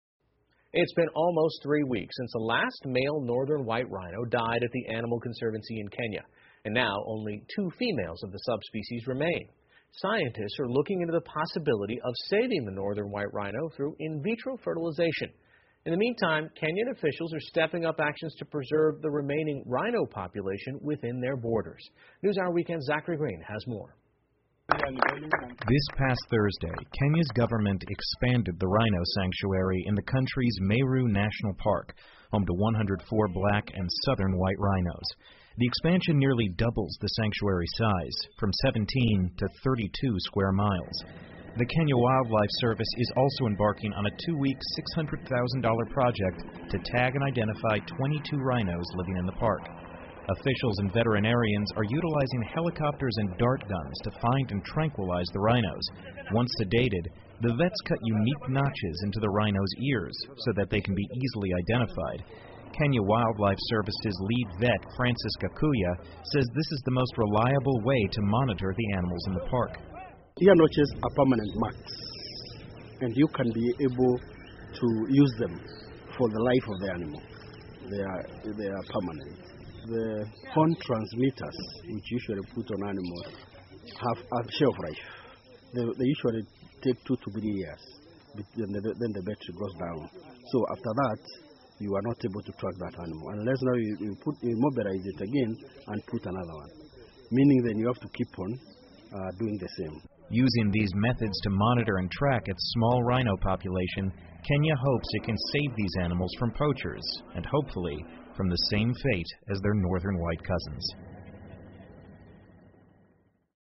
PBS高端访谈:肯尼亚在雄性白犀死后继续保护犀牛亚种 听力文件下载—在线英语听力室